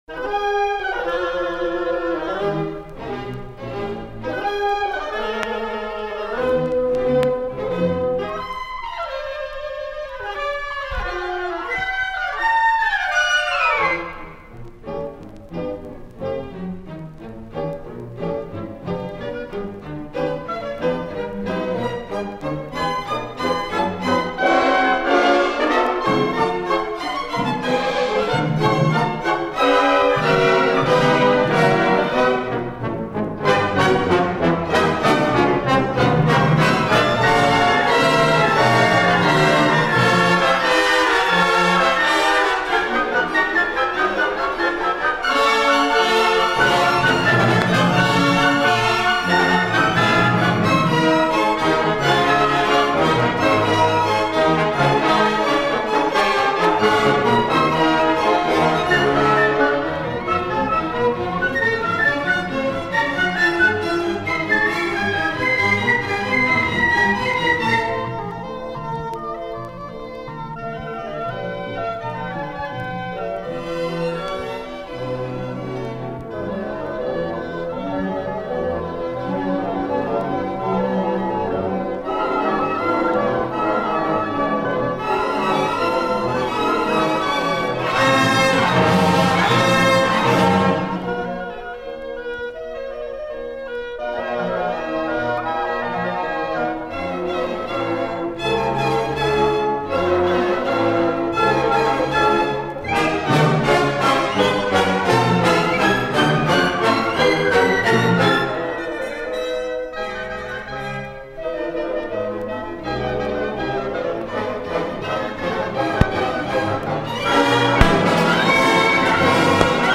simfoninė poema